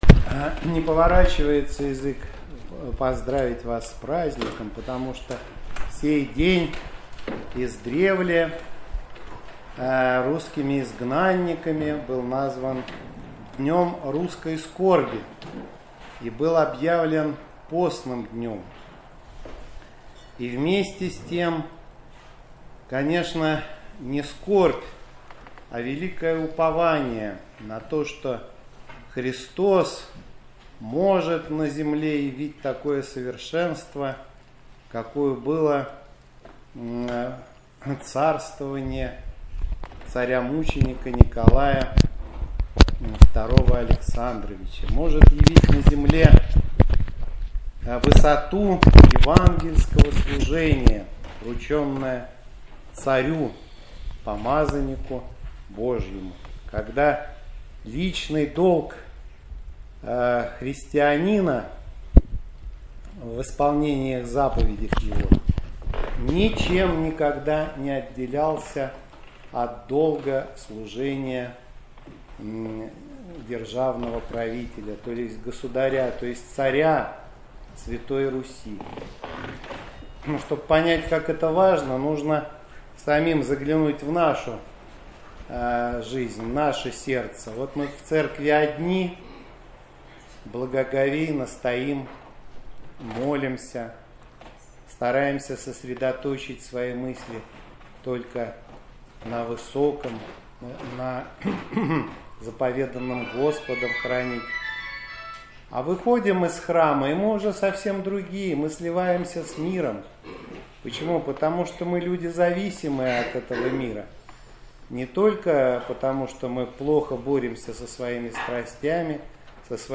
Проповеди 2021